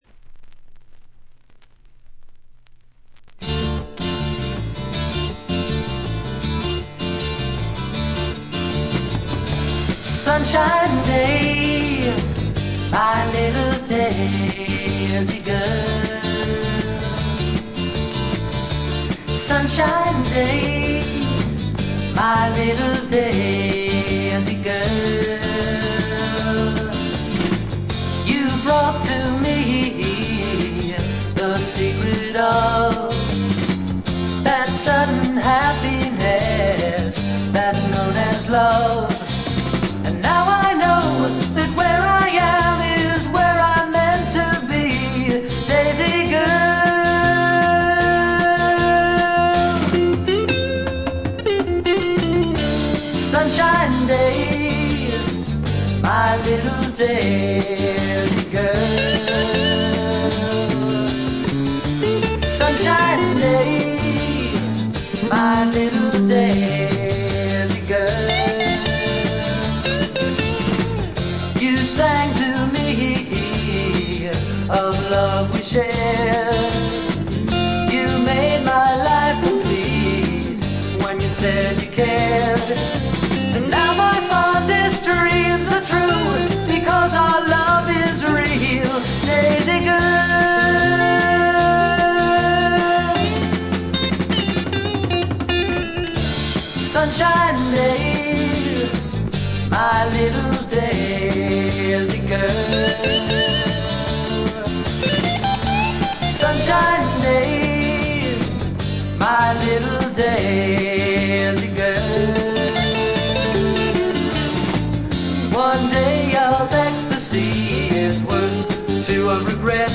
テキサスのシンガー・ソングライターが残した自主制作快作です。。
きらきらときらめくアコギの音、多くのマニアをノックアウトしたメローでクールな楽曲、時おりふっと見せるダウナーな感覚。